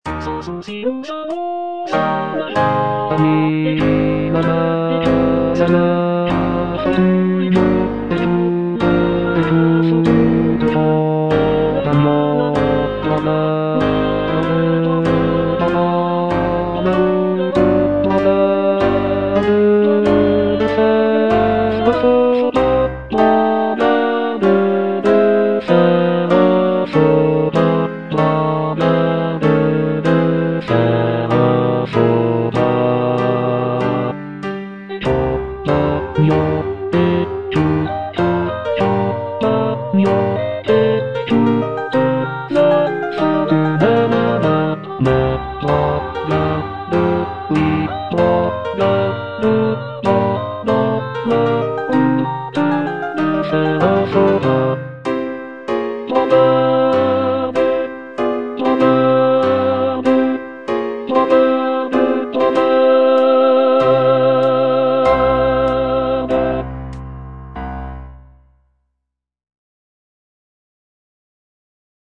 G. BIZET - CHOIRS FROM "CARMEN" Ami, là-bas est la fortune (bass I) (Voice with metronome) Ads stop: auto-stop